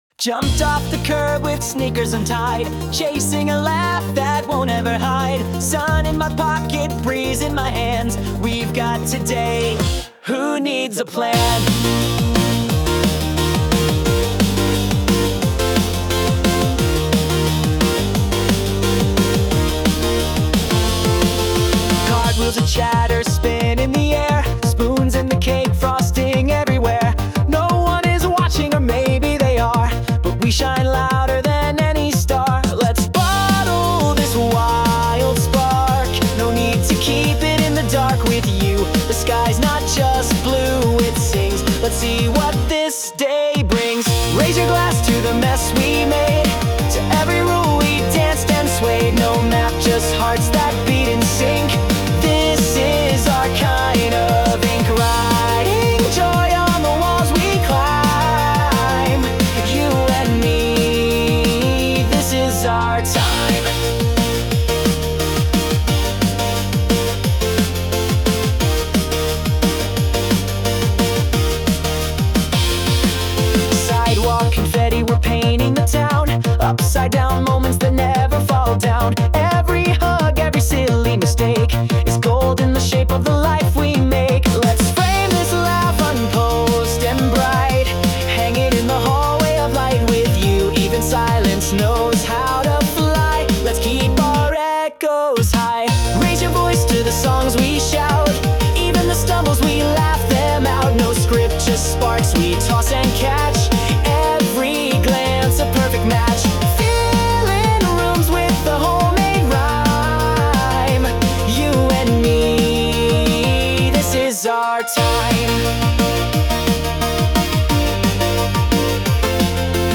洋楽男性ボーカル著作権フリーBGM ボーカル
男性ボーカル洋楽洋楽 男性ボーカルオープニングムービー入場・再入場乾杯ケーキ入刀ポップスアップテンポロック元気
著作権フリーオリジナルBGMです。
男性ボーカル（洋楽・英語）曲です。
そんなイメージを、少しコミカルに、でも心から明るく描きました